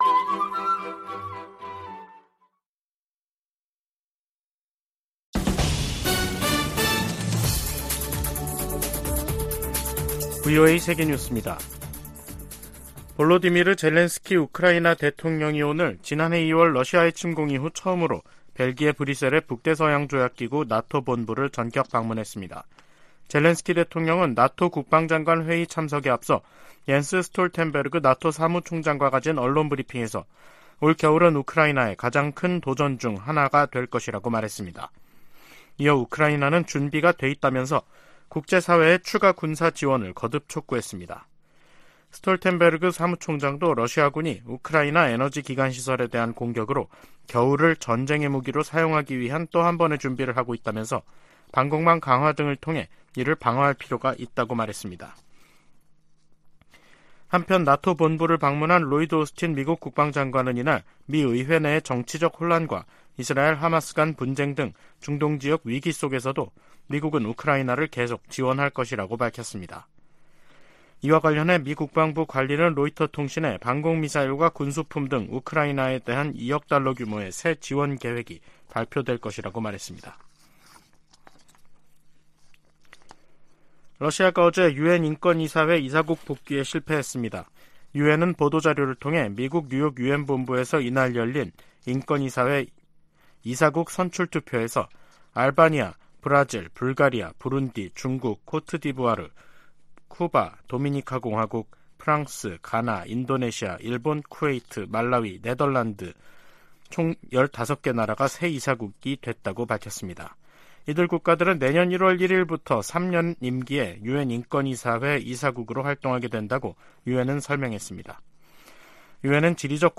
VOA 한국어 간판 뉴스 프로그램 '뉴스 투데이', 2023년 10월 11일 2부 방송입니다. 조 바이든 미국 대통령이 이스라엘에 대한 하마스의 공격을 테러로 규정하고 이스라엘에 전폭적 지원을 약속했습니다. 한국 군 당국이 하마스의 이스라엘 공격 방식과 유사한 북한의 대남 공격 가능성에 대비하고 있다고 밝혔습니다. 미 국무부가 하마스와 북한 간 무기 거래 가능성과 관련해 어떤 나라도 하마스를 지원해선 안 된다고 강조했습니다.